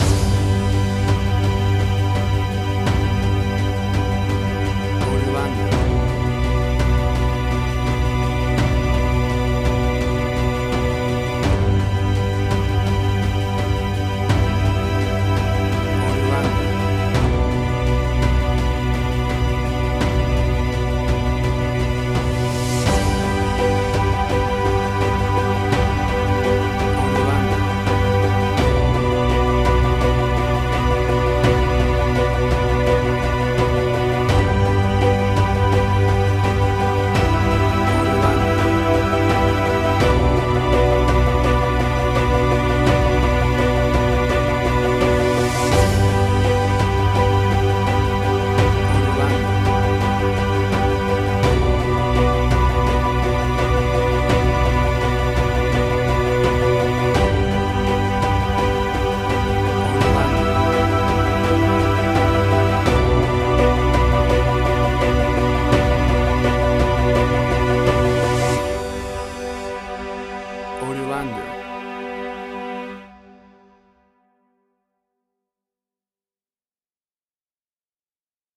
WAV Sample Rate: 16-Bit stereo, 44.1 kHz
Tempo (BPM): 84